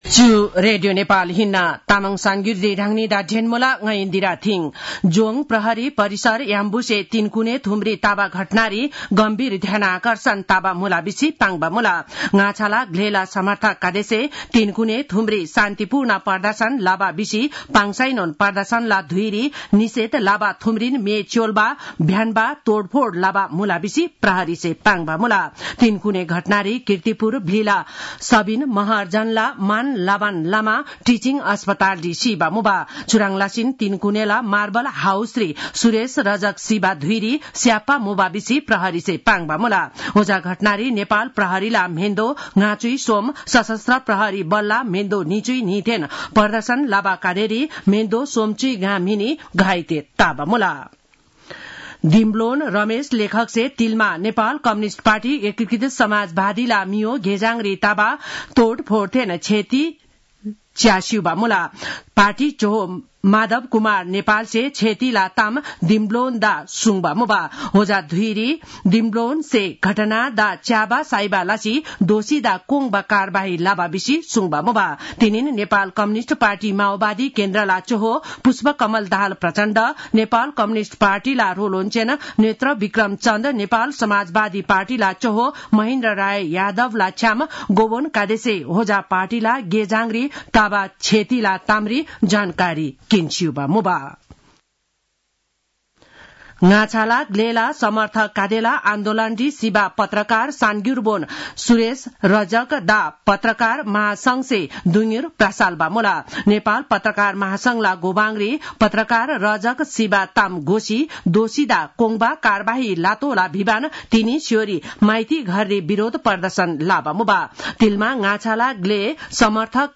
तामाङ भाषाको समाचार : १६ चैत , २०८१